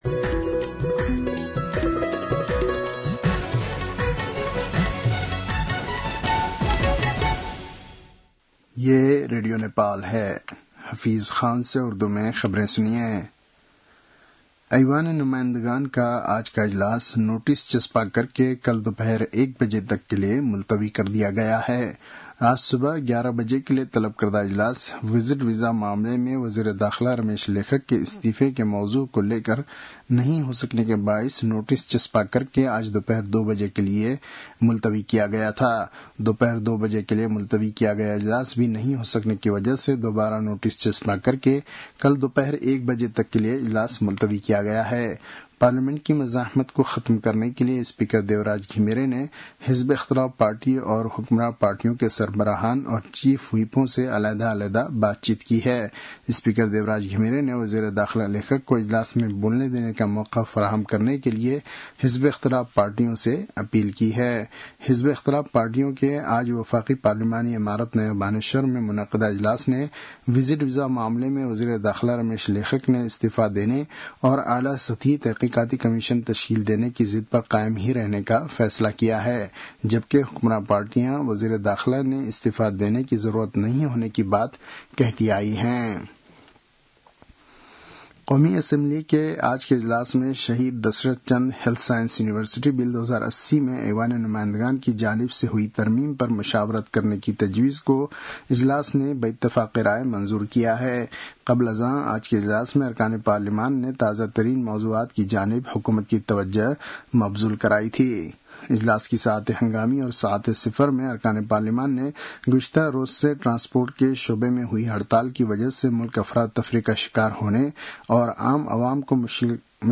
उर्दु भाषामा समाचार : २० जेठ , २०८२